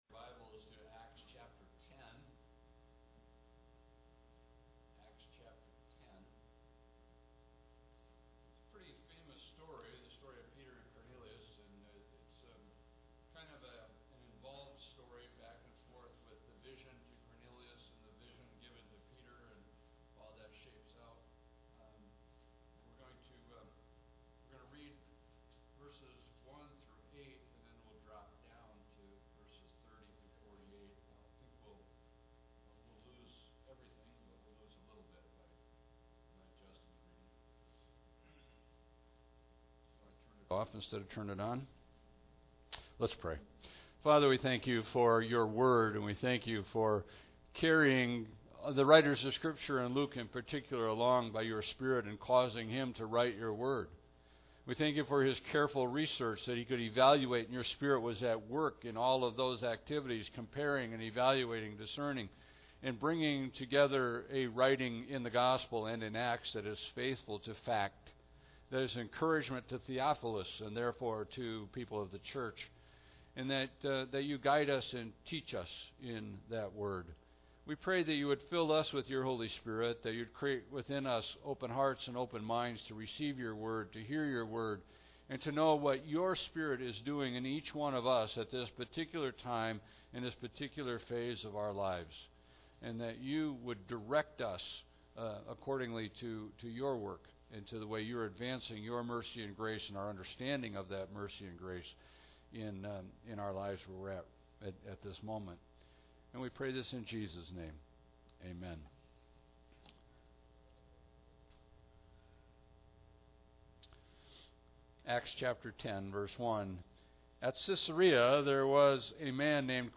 Mic is off for the first minute.